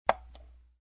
Звуки компьютерной мышки
Щелчки мыши